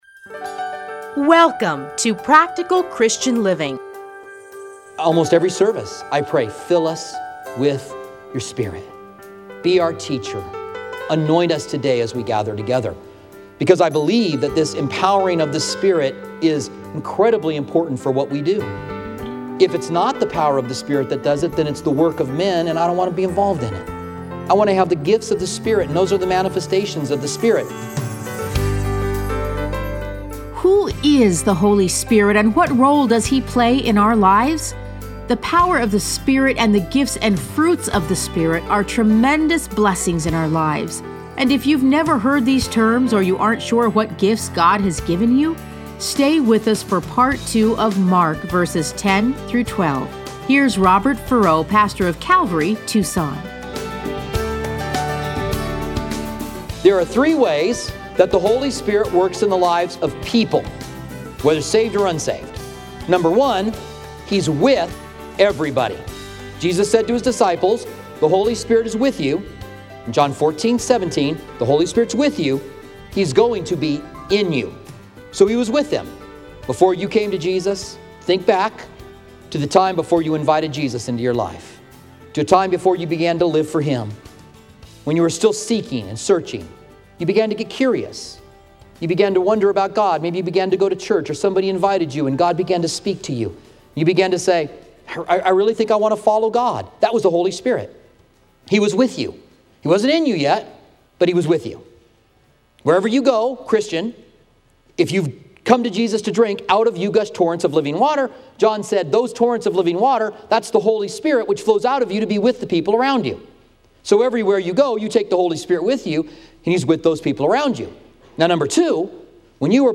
Listen to a teaching from Mark 1:10-12.